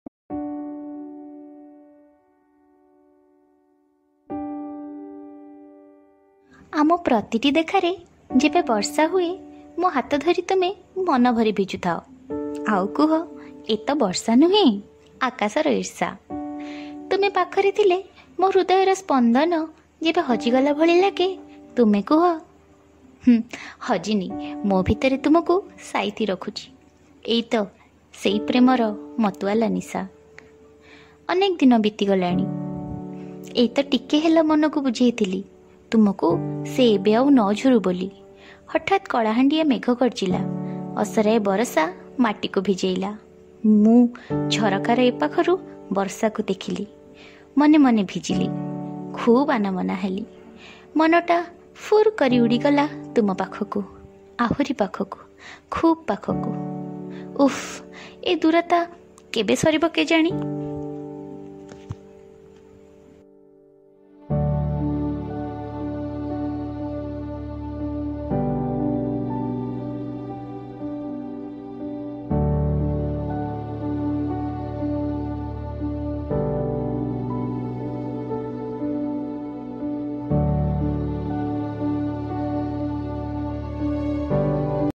Odia Poem